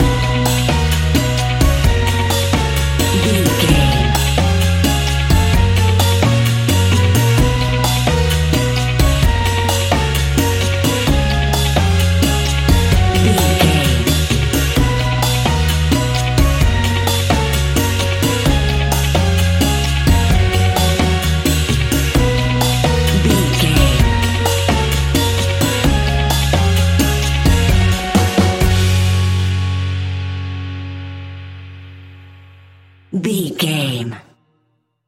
Uplifting
Ionian/Major
F#
steelpan
worldbeat
calpso groove
drums
percussion
bass
brass
guitar